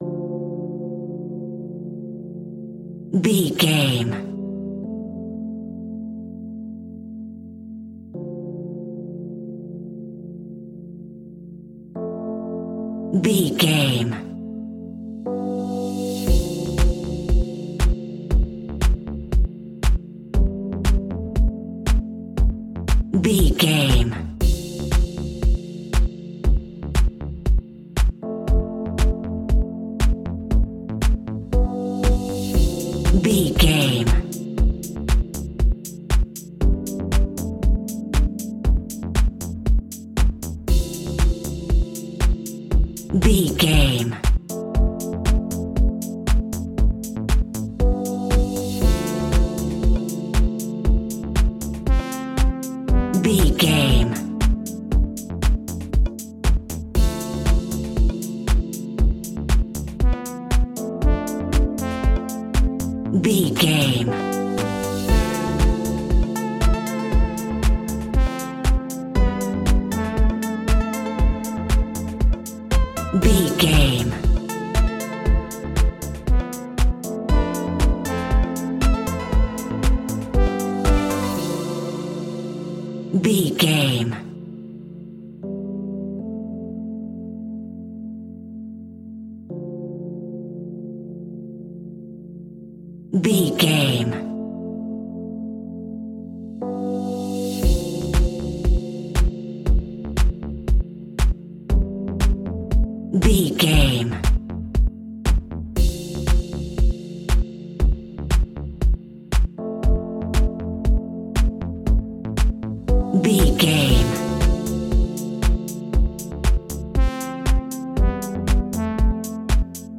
Aeolian/Minor
D
groovy
peaceful
meditative
smooth
drum machine
synthesiser
electro house
synth leads
synth bass